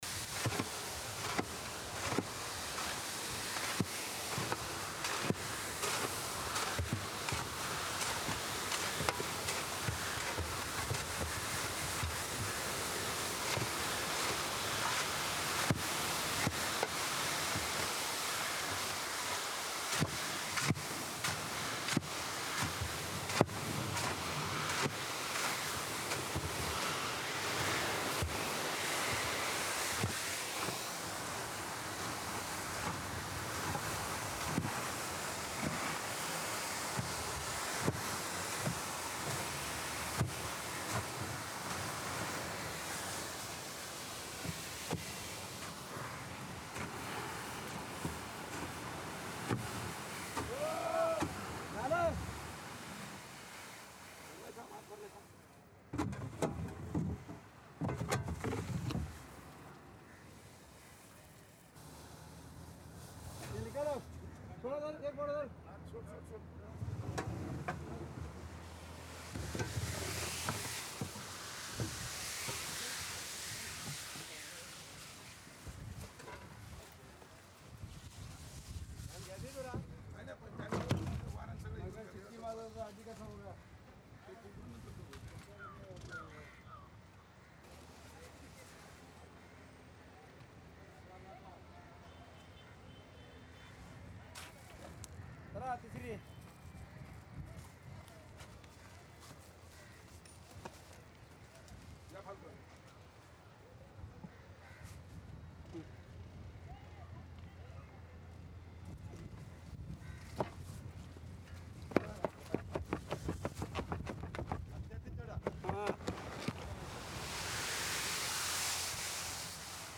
This ambience captures the calm yet powerful atmosphere of a dry-weather beach, filled with the natural rhythm of sea waves breaking gently on the shore. The sound includes soft ocean swells, light foam crackles, distant wave crashes, and the airy openness of a wide coastline.
The ambience stays clean and crisp—no heavy winds—just the pure sound of the sea in dry, clear weather. Occasional seagull calls and distant human presence blend lightly, adding realism without overpowering. Perfect for creating a peaceful, breezy, refreshing beach mood.
This soundscape delivers the essence of a sunny beach day, giving a relaxing, open, and airy seaside feel.
Medium–Soft
Beach, Ocean, Shoreline
Calm, Refreshing, Relaxing
Sea Shore Ambience
Twentyseven-Dry-Sand-Shovel.mp3